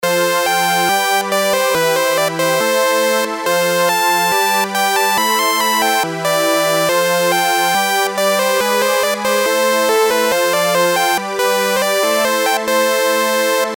シンセ音作りサンプル素リード
シンセ音作りサンプル素リード.mp3